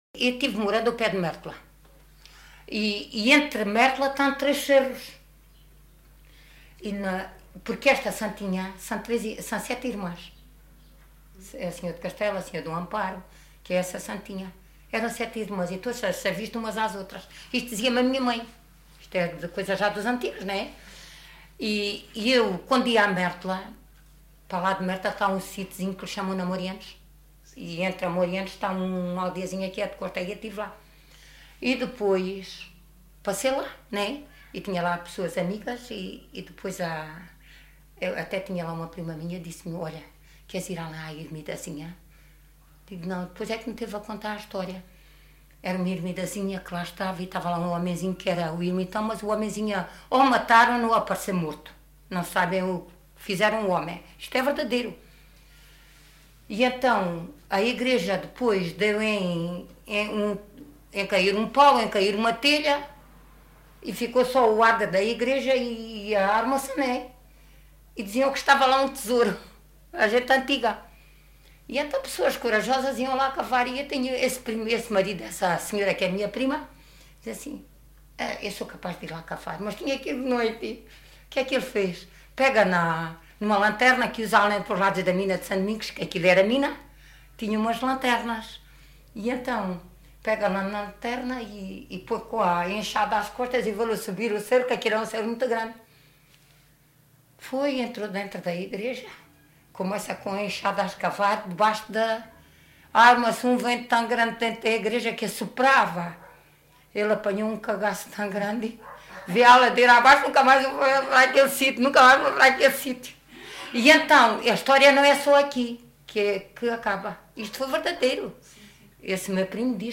LocalidadeAljustrel (Aljustrel, Beja)